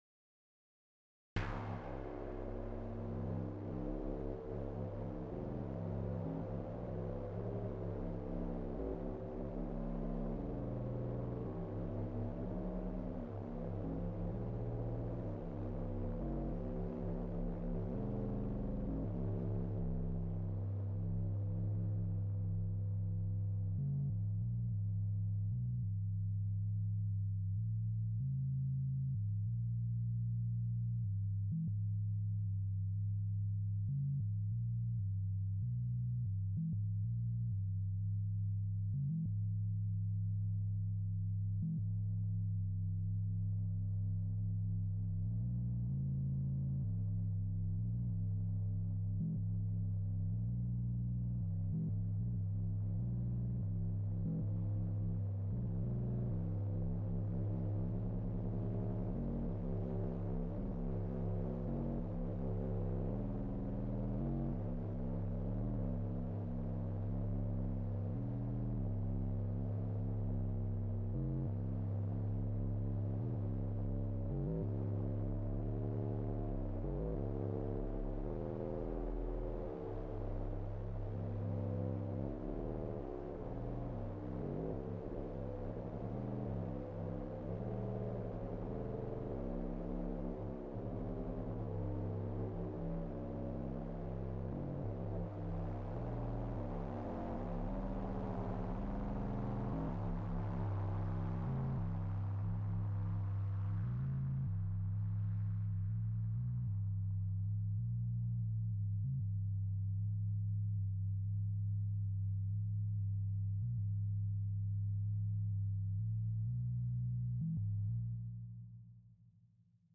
Meanwhile, here’s a single track sketch with no FX block.
As usual a long trig with 15 lock trigs for Inharmonicity, LFOs for MENV and MDFB.